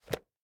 04_书店内_点击书本.ogg